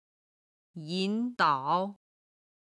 今日の振り返り！中国語発声